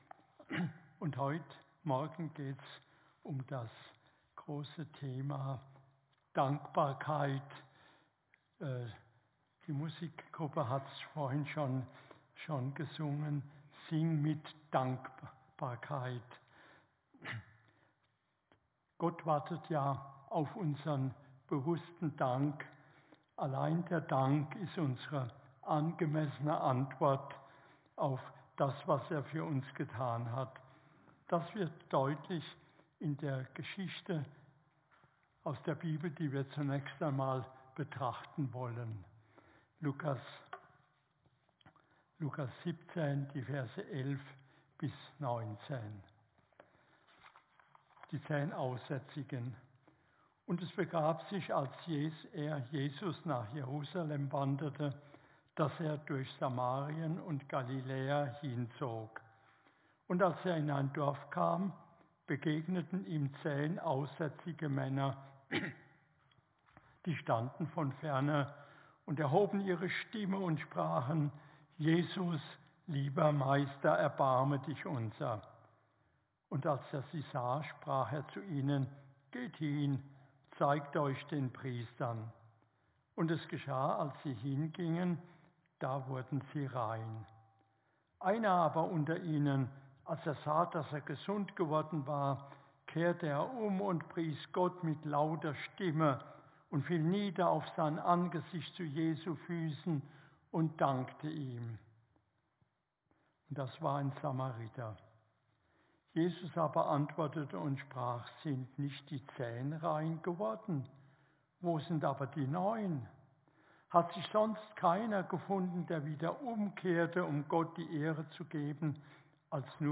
Predigt-21.4.24.mp3